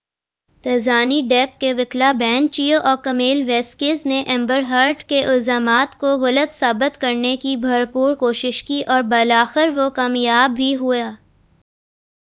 deepfake_detection_dataset_urdu / Spoofed_TTS /Speaker_01 /262.wav